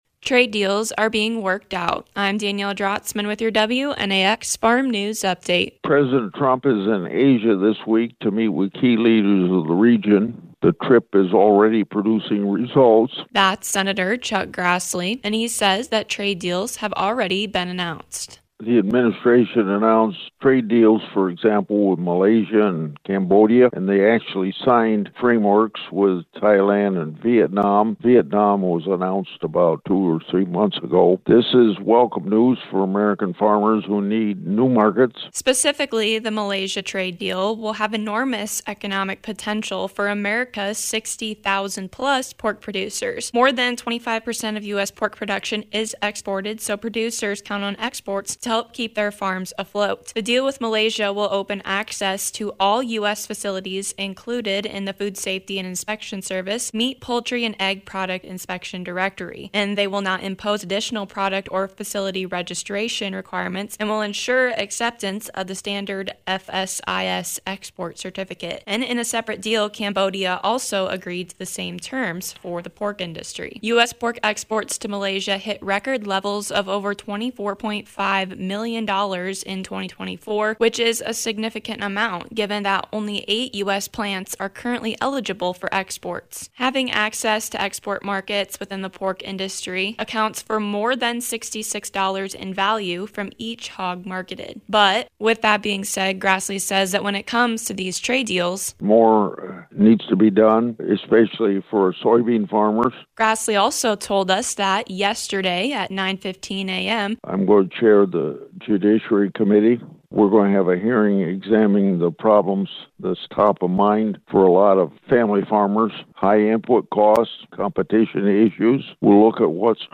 Trade deal are being worked out for the Ag industry. Hear from Senator Chuck Grassley.